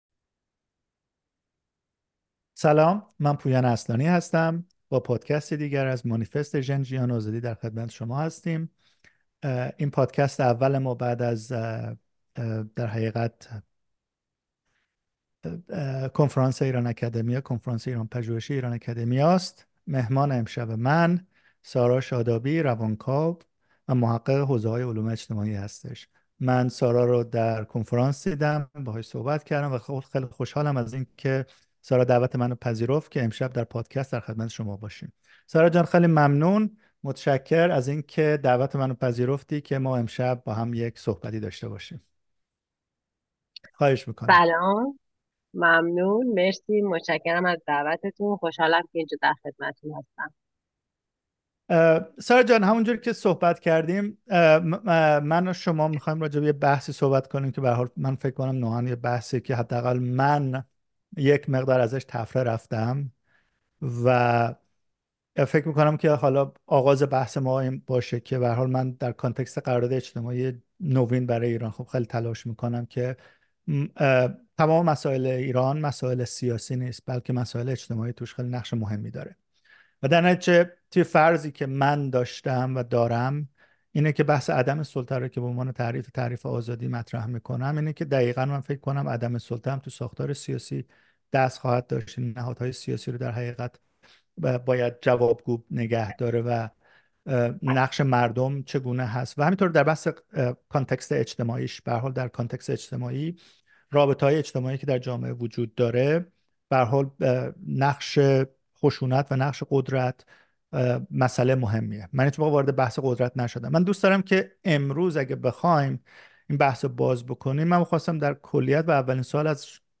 گفت‌وگو درباره قدرت، اتوریته و قرارداد اجتماعی